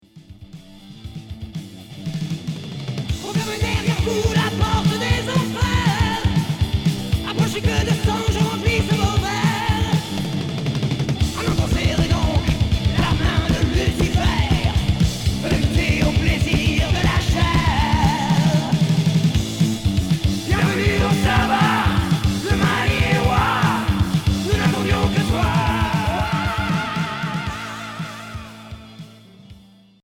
Hard